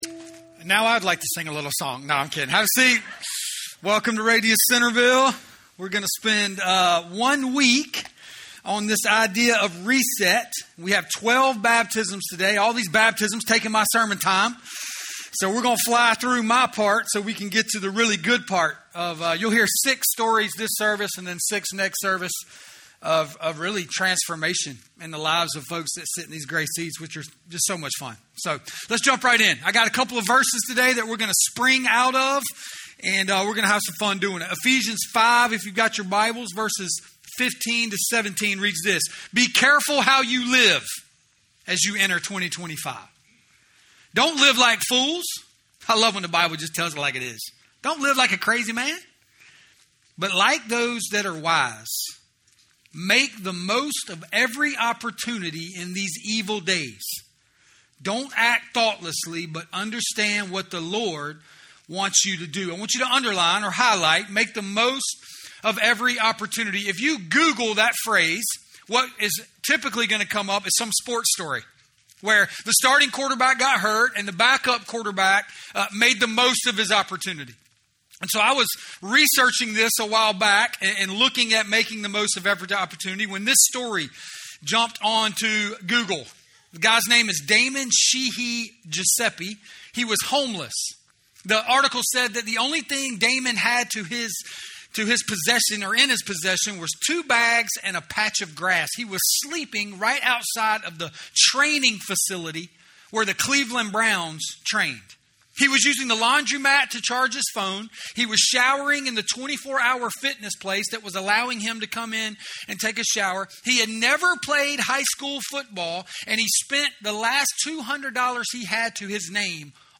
From Campus: "RADIUS Centerville"